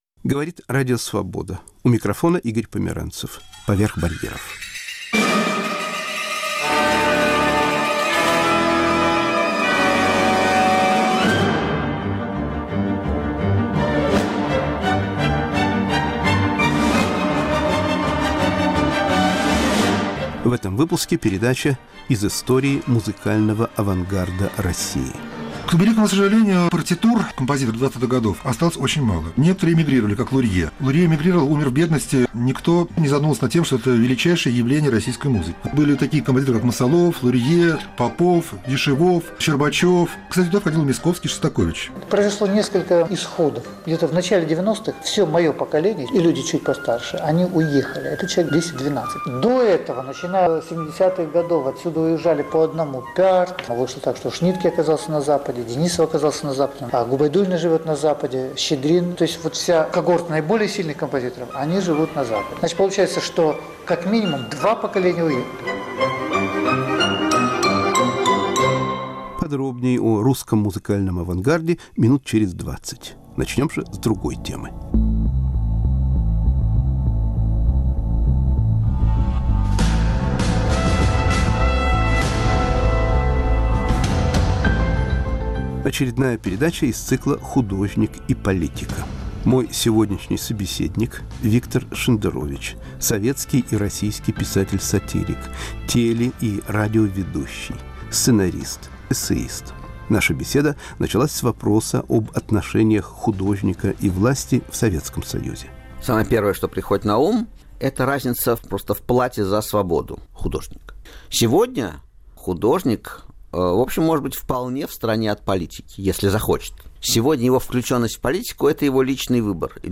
Разговор с писателем Виктором Шендеровичем. ** Из истории музыкального авангарда в России.